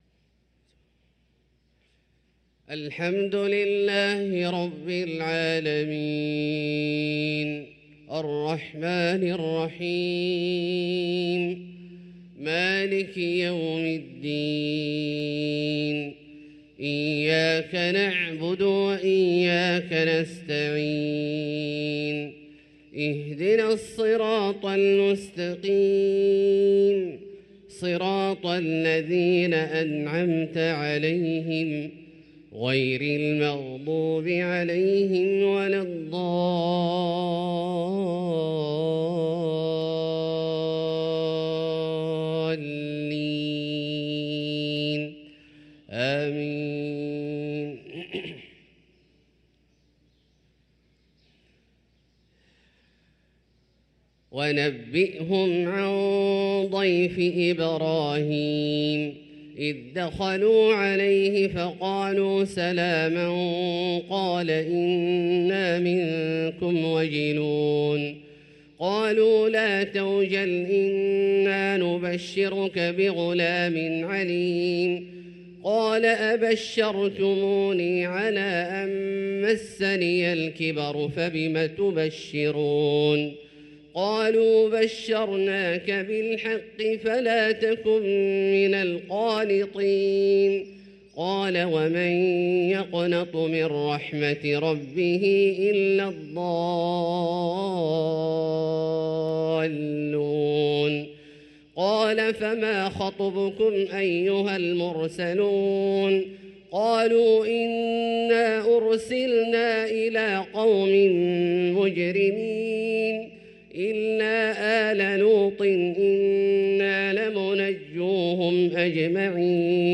صلاة الفجر للقارئ عبدالله الجهني 22 جمادي الأول 1445 هـ
تِلَاوَات الْحَرَمَيْن .